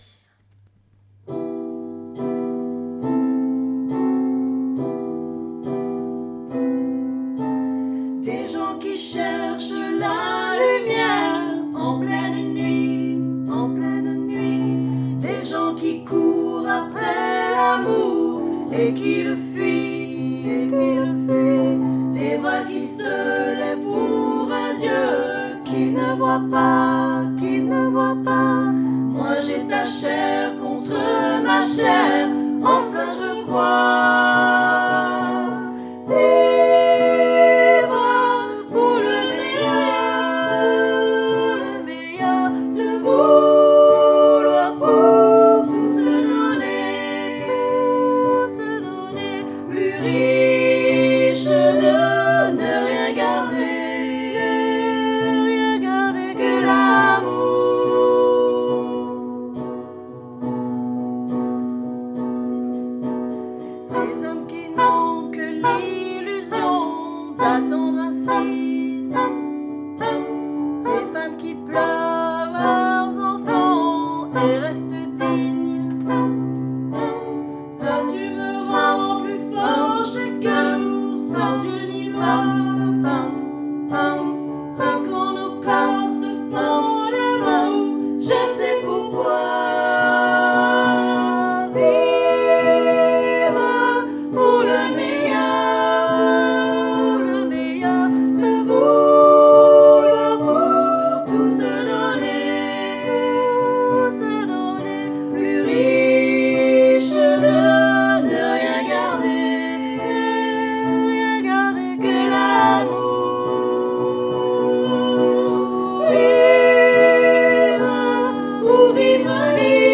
Enregistrements de travail
Vivre-pour-le-meilleur-tutti.mp3